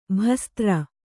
♪ bhastra